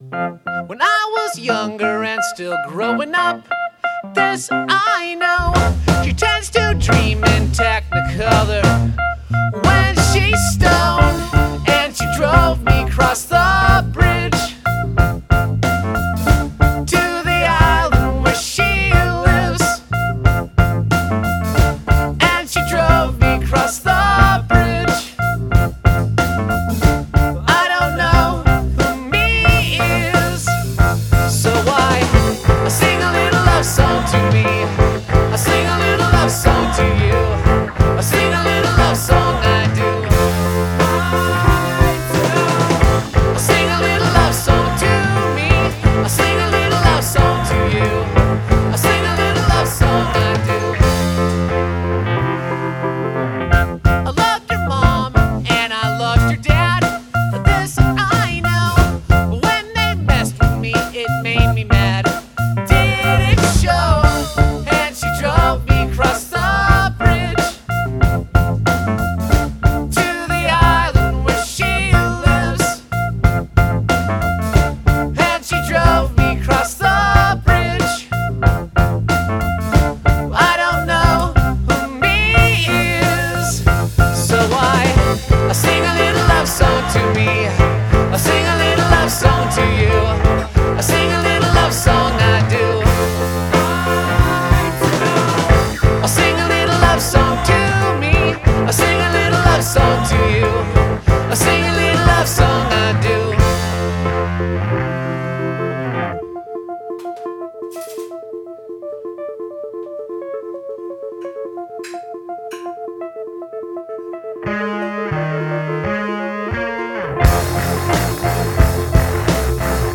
Keyboards
a fun pop album